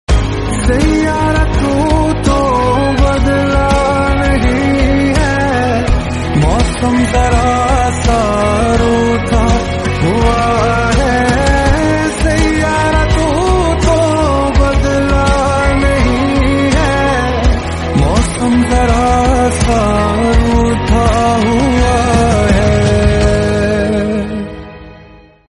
soulful Hindi ballad
🎧 Genre: Bollywood Romantic / Melody